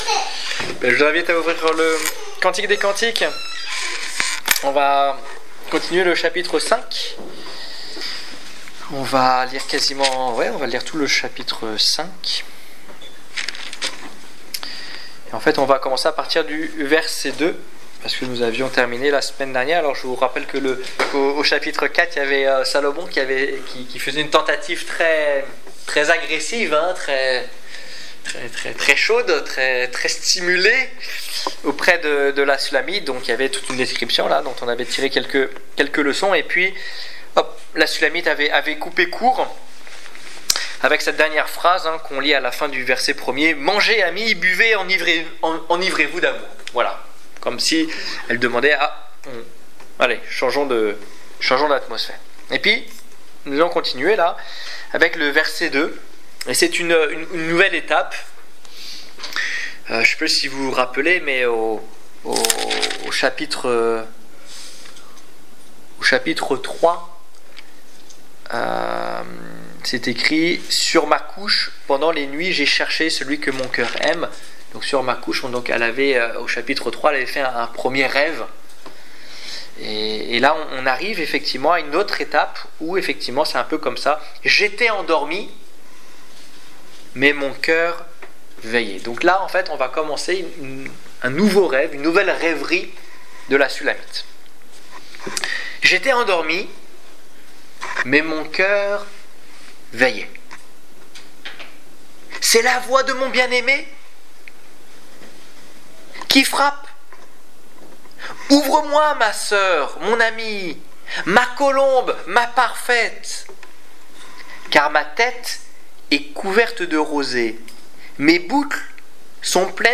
Étude biblique du 9 septembre 2015